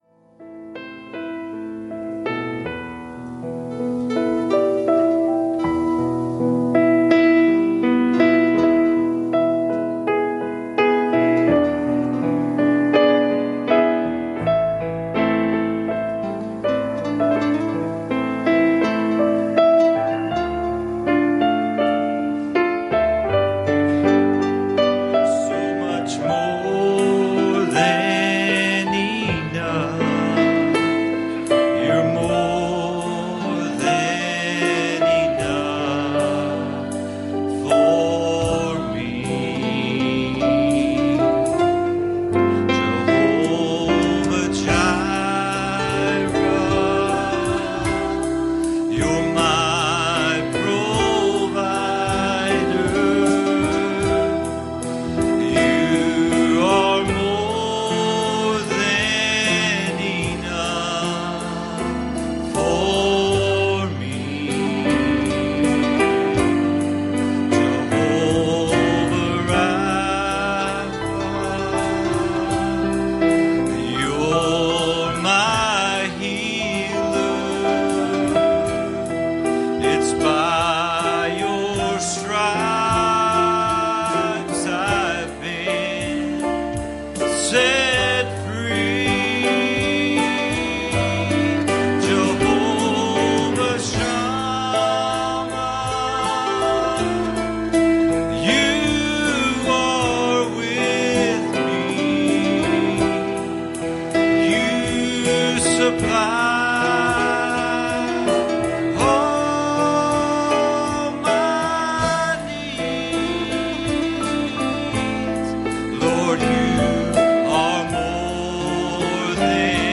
Passage: Ephesians 5:31 Service Type: Sunday Morning